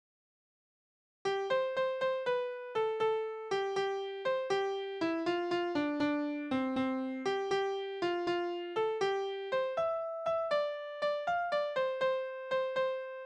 Balladen: Edelmann und Höriger
Tonart: C-Dur
Taktart: 6/8
Tonumfang: Oktave, Quarte
Besetzung: vokal